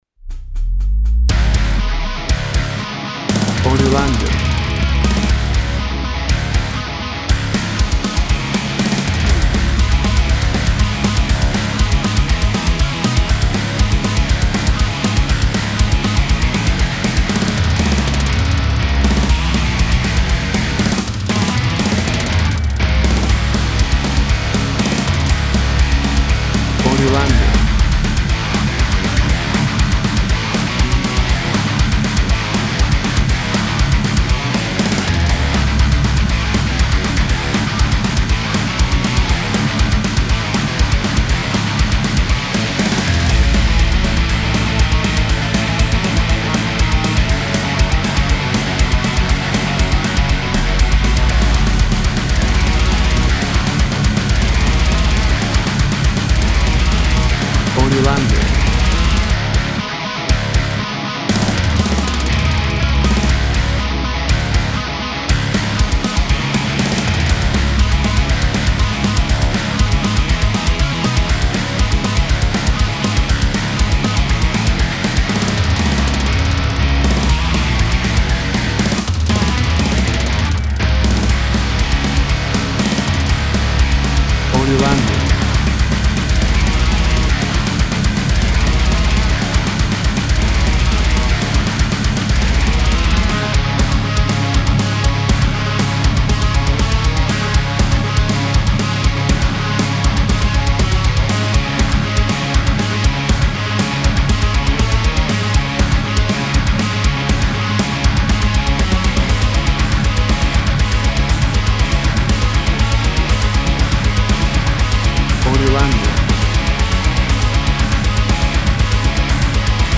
An upbeat extreme metal track. High energy and aggression.
Tempo (BPM) 240